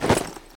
ui_interface_260.wav